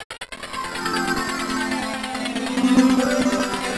That Soul Pad D 127.wav